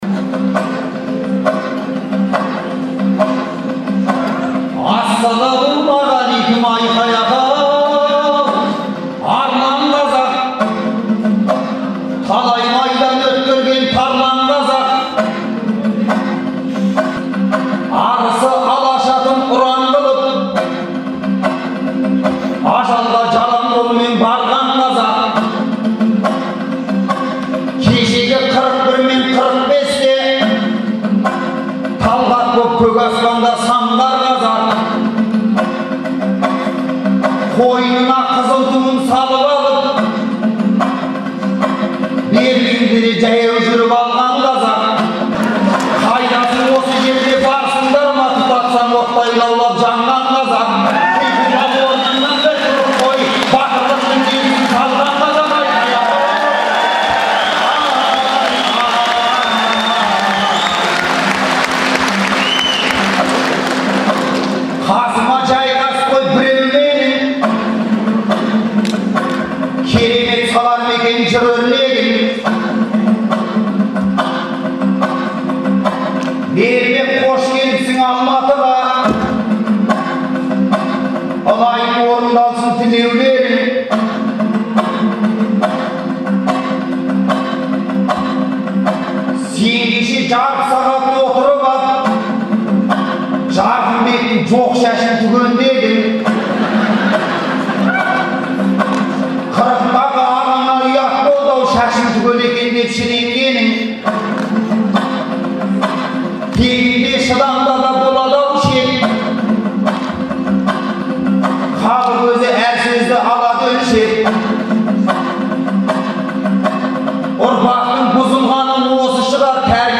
Айқай-шуға ұласқан бұл айтыстың үзіндісін Азаттық бұған дейін берген.